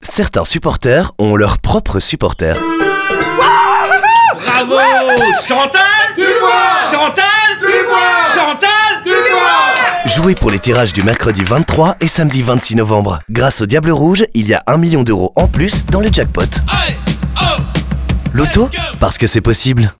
La campagne vit également en radio, où des scènes typiques de stade ont trouvé leur chemin jusqu’au marchand de journaux.